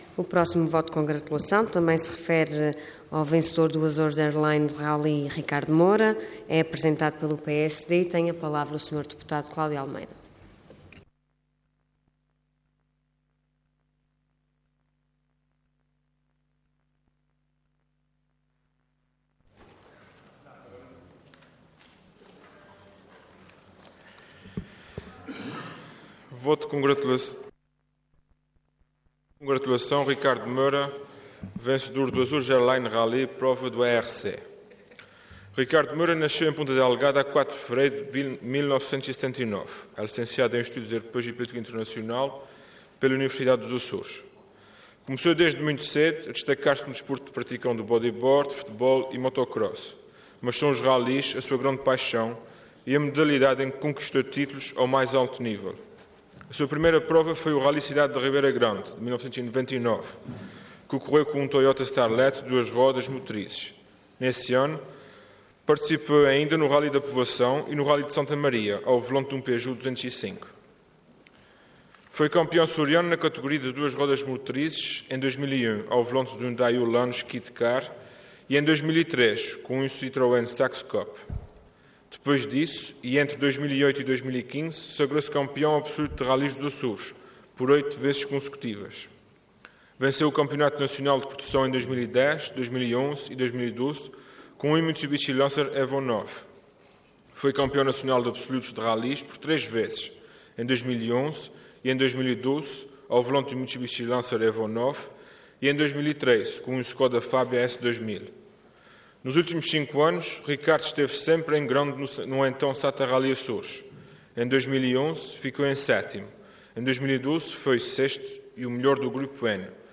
Detalhe de vídeo 15 de junho de 2016 Download áudio Download vídeo Processo X Legislatura Ricardo Moura vencedor do Azores Airlines Rally - Prova do ERC Intervenção Voto de Congratulação Orador Cláudio Almeida Cargo Deputado Entidade PSD